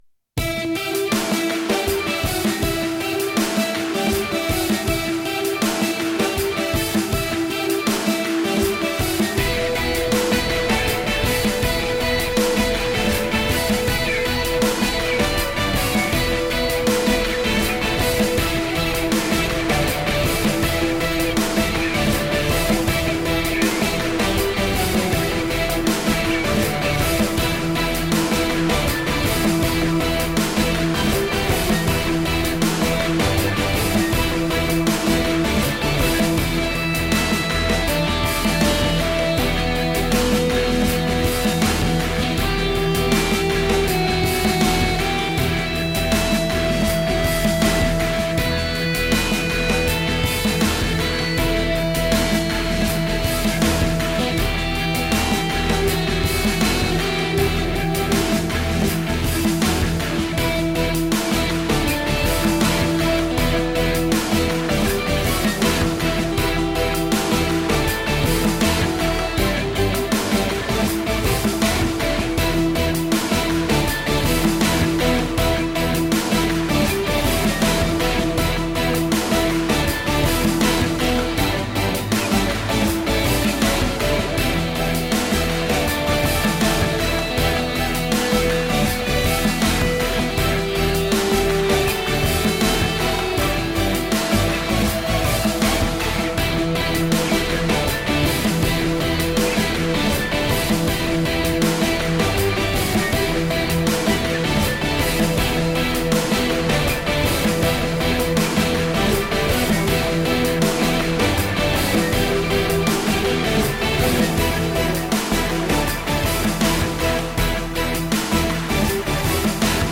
Upbeat track for sports and fighting.
Upbeat track with synths and drums for sports and fighting.